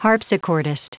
Prononciation du mot harpsichordist en anglais (fichier audio)
Prononciation du mot : harpsichordist